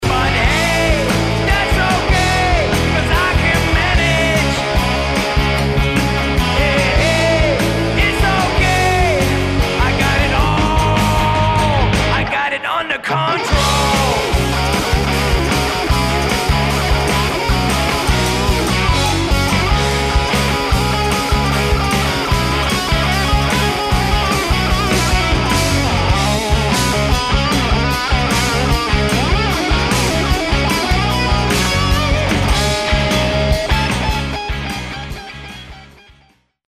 NO BULLSHIT ROCK & ROLL!